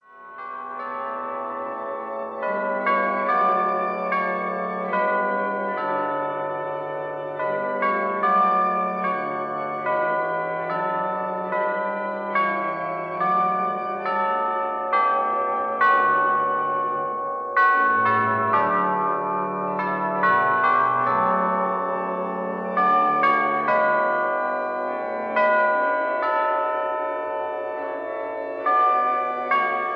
chimes